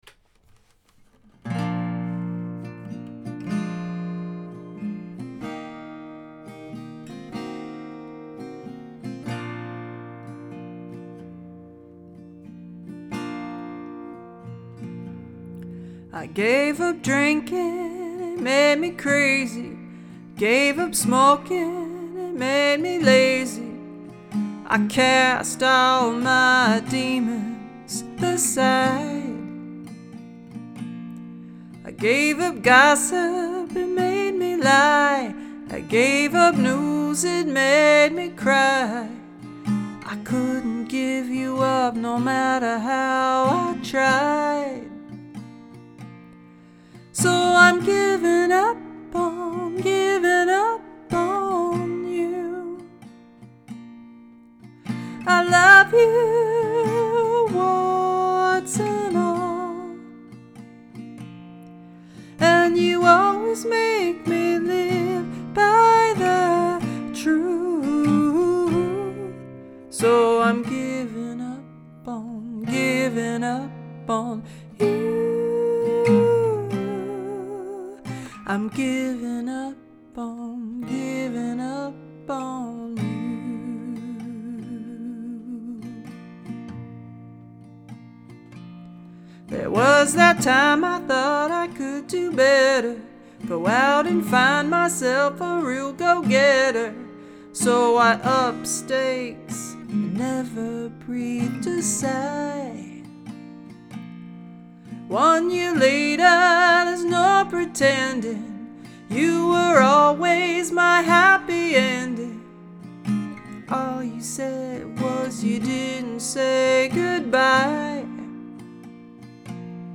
A rough sketch of a new song